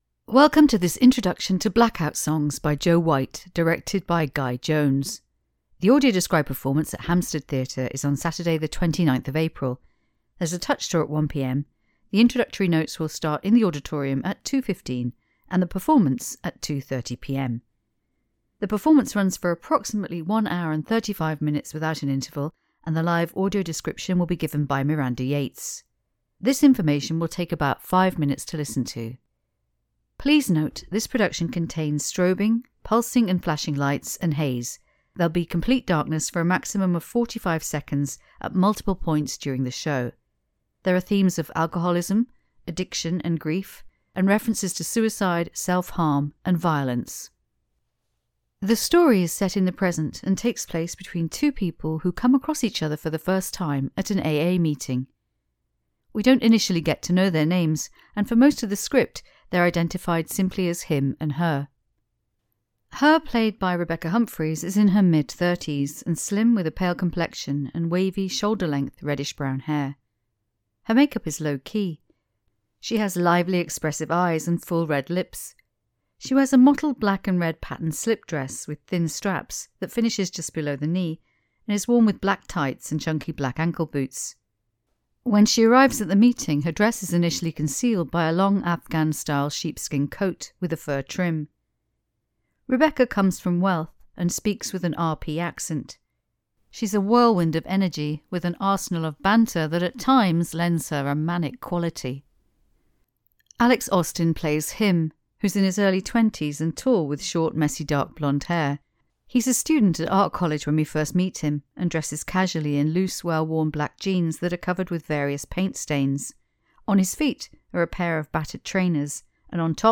An Audio Introduction is available for this production. This is a pre-recorded introduction describing the set, characters and costumes and includes an interview with the cast.
BLACKOUT-SONGS-Introduction.mp3